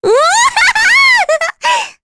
Rehartna-Vox_Happy8_kr.wav